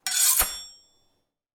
SWORD_10.wav